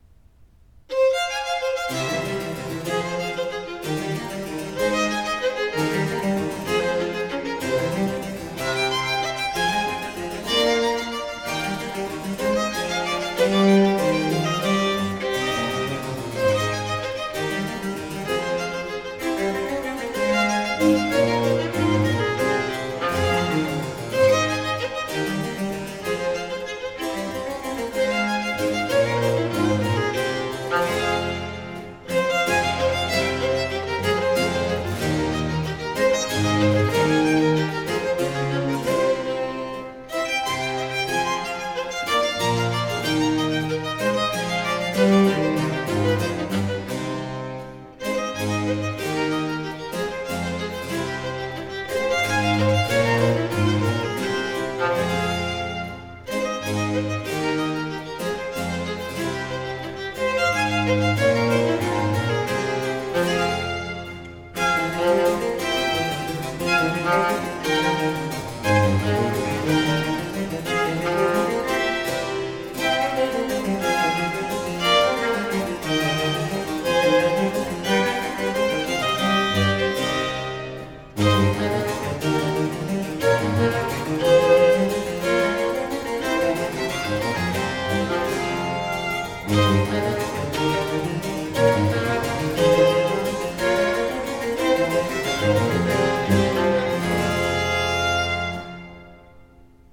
Vivace